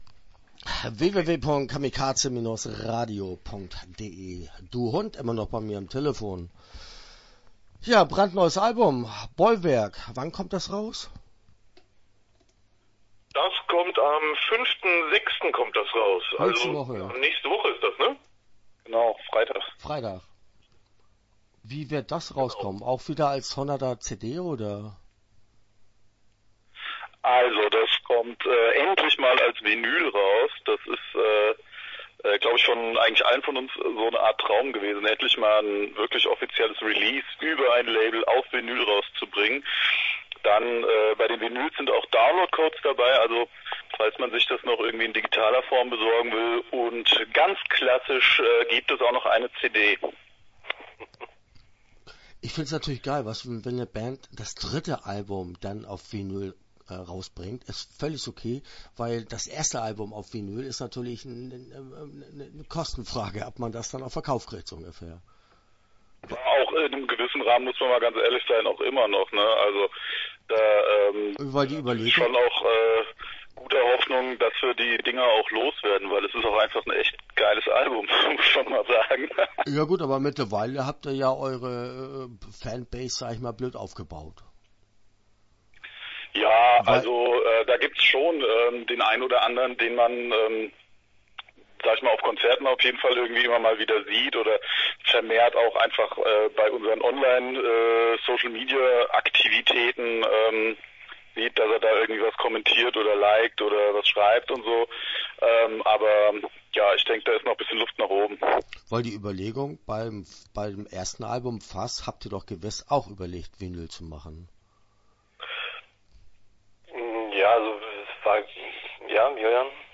DV HVND - Interview Teil 1 (11:15)